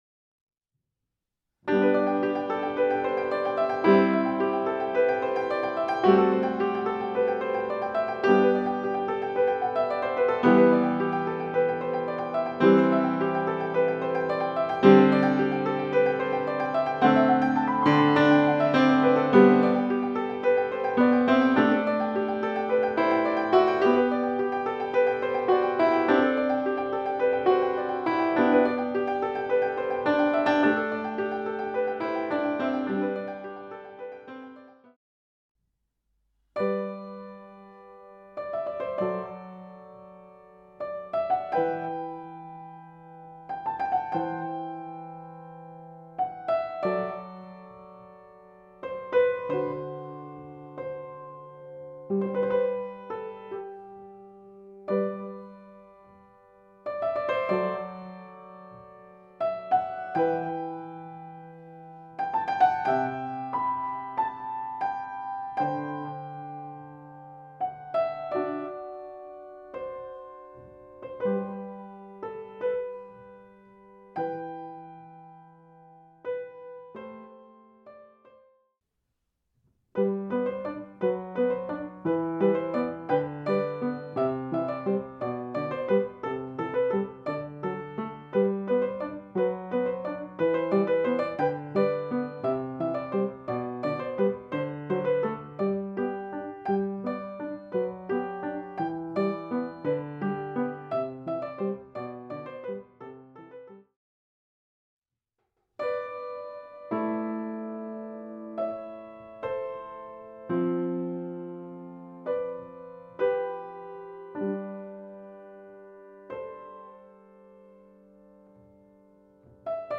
für Klavier Solo